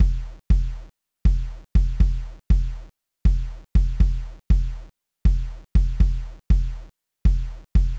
Squelchy sound of bass drum (from Hydrogen drum track)
I have used Hydrogen to create a drum track, but when I import into Audacity it has a weird squelchy sound (see attached link).
Squelchy sound = low* bit-depth